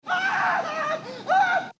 Grito